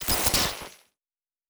pgs/Assets/Audio/Sci-Fi Sounds/Electric/Glitch 2_07.wav at 7452e70b8c5ad2f7daae623e1a952eb18c9caab4
Glitch 2_07.wav